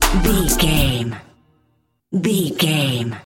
Aeolian/Minor
synthesiser
drum machine
Funk